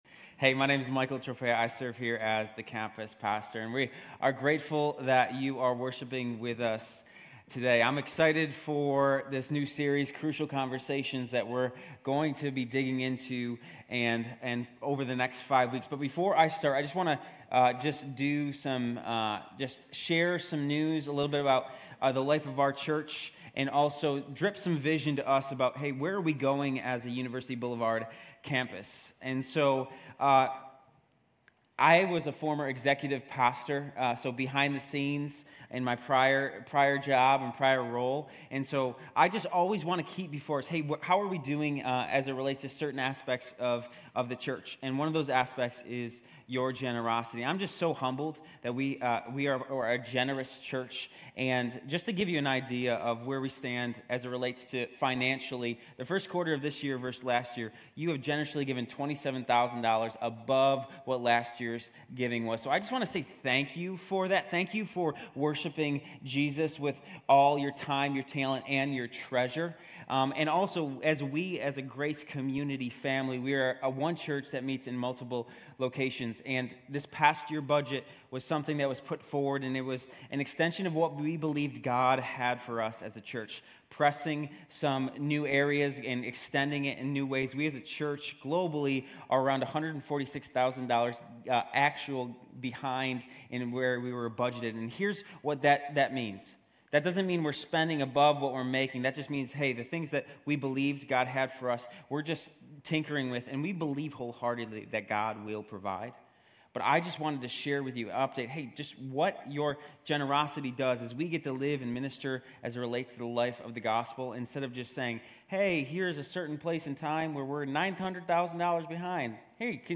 Grace Community Church University Blvd Campus Sermons Crucial Conversations: Acts 10 Oct 15 2023 | 00:43:49 1x 00:00 / 00:43:49 Subscribe Share RSS Feed Share Link Embed